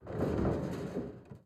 ca37fcf28b Divergent / mods / Soundscape Overhaul / gamedata / sounds / ambient / soundscape / underground / under_15.ogg 40 KiB (Stored with Git LFS) Raw History